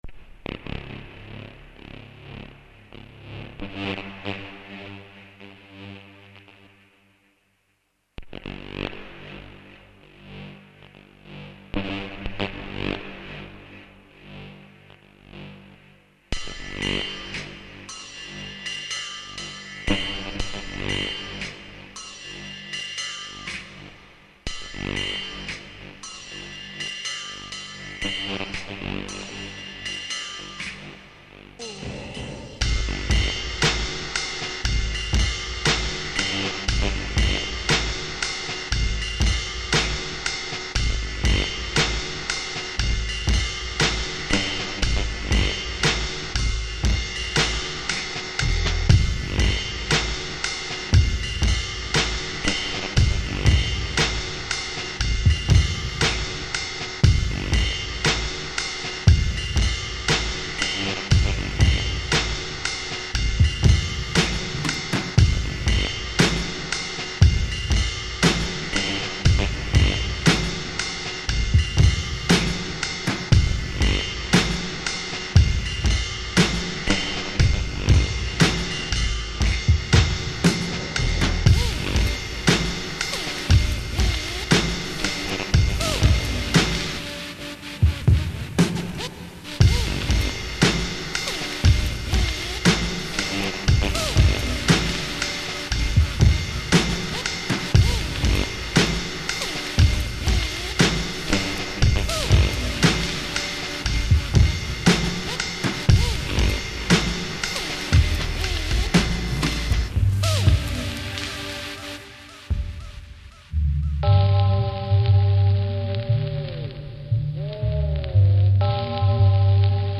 pumped up...blissed out electronic beats
fusing the best elements of big beats and trip hop